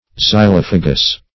Search Result for " xylophagous" : The Collaborative International Dictionary of English v.0.48: Xylophagous \Xy*loph"a*gous\, a. [Gr. xylofa`gos eating wood; xy`lon wood + fagei^n to eat.]